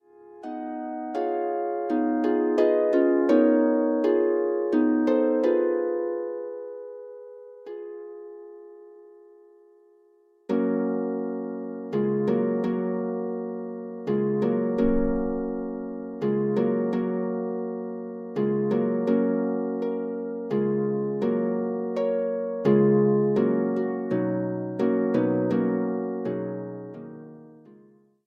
arranged for solo pedal harp.